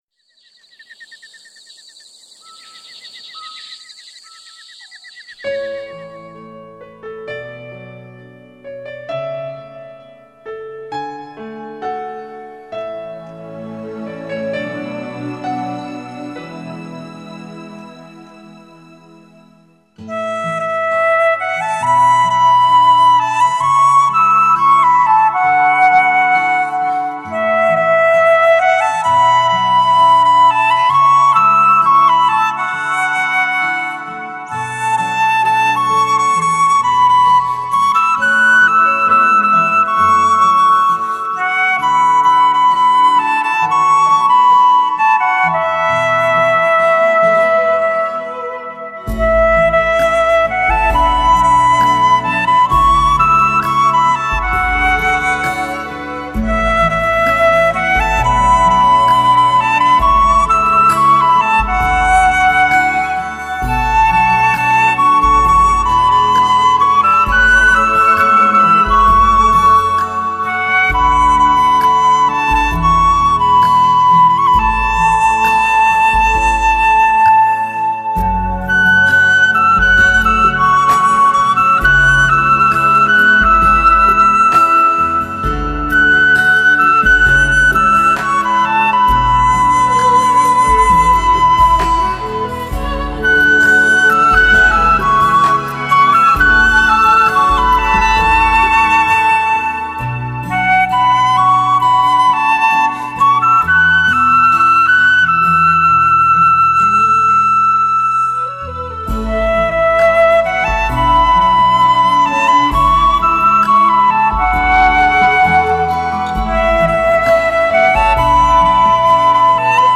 休闲减压音乐系列
用行云流水般的音符阐释浪漫情怀
完美的音效 精彩的演奏 给您超值的音乐享受
长笛是所有乐器中声音最甜美的，在较高的音域中就像鸟鸣一样。
24bit-192KHz母带数位制作
本母带经由美国太平洋微音公司HDCD贰型处理器处理